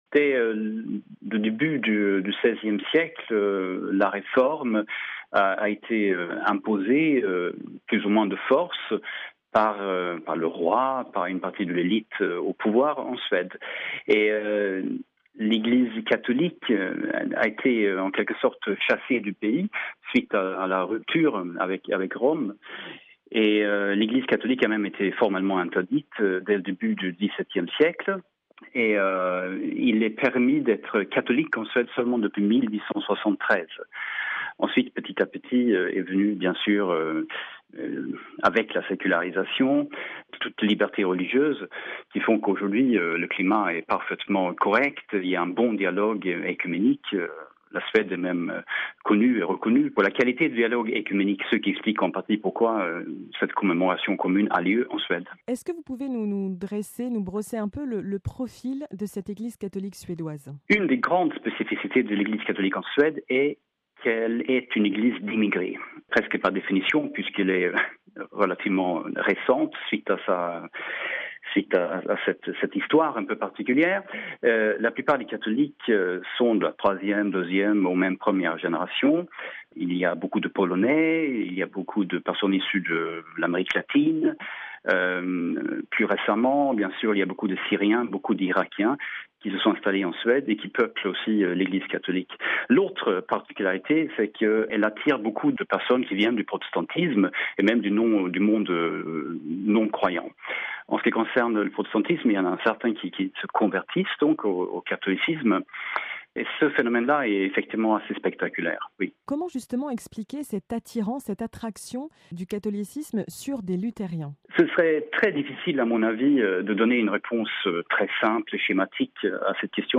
(RV) Entretien - La Suède se prépare à accueillir le Pape François pour son 17e voyage apostolique.